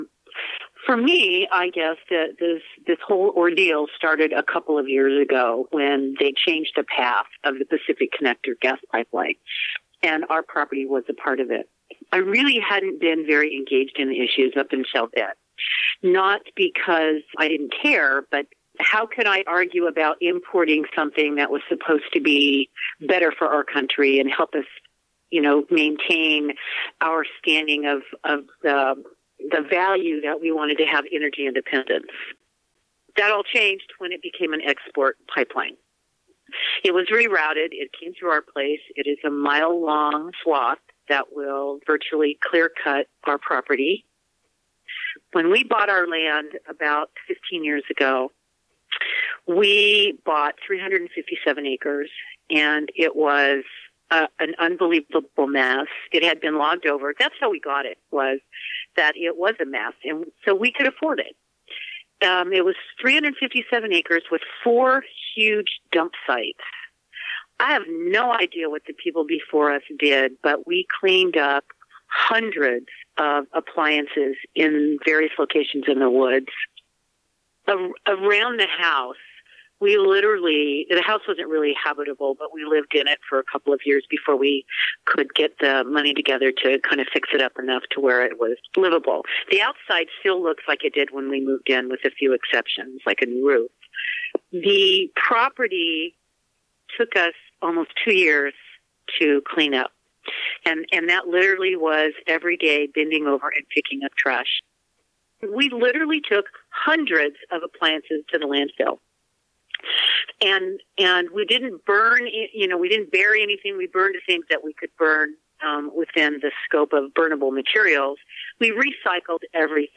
From our original phone conversation: Please excuse the phone-quality audio.